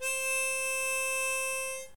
HarmonicaC.ogg